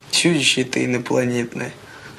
Саша Белый из Бригады говорит чудище ты инопланетное звук